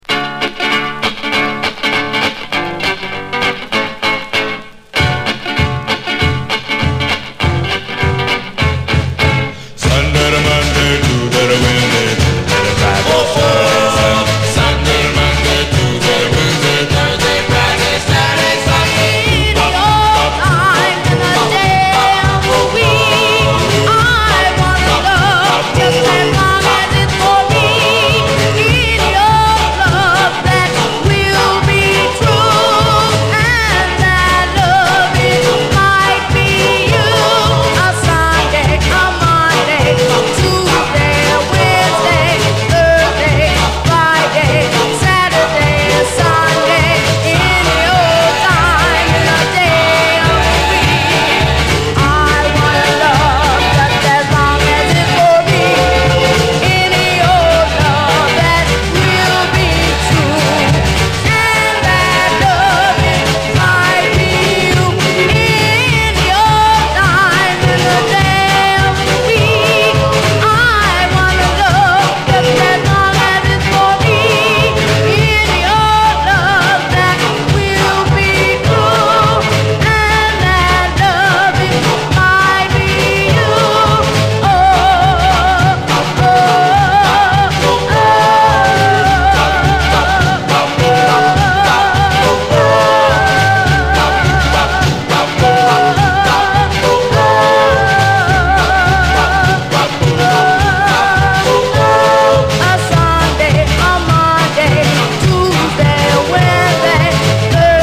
DOO WOP, OLDIES, 7INCH
ギターのイントロも印象的、胸を締め付ける最高の青春パーティー・ドゥーワップ！